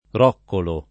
roccolo [ r 0 kkolo ] s. m.